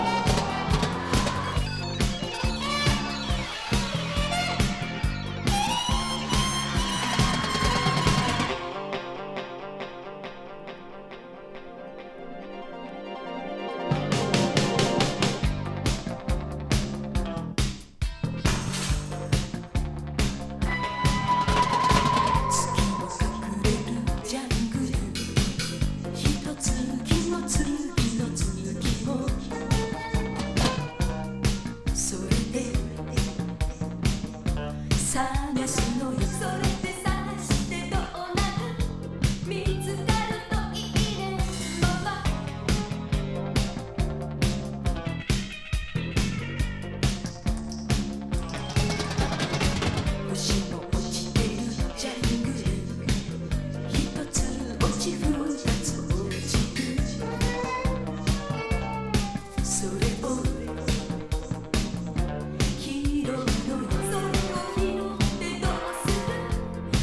NWレゲエ
メロウ・バラード